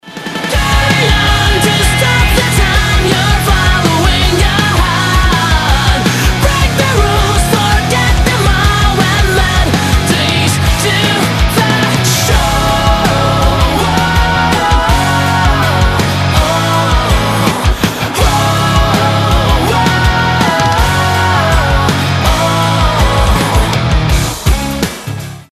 рок
Жанр: Alternative Rock